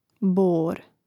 bȏr bor1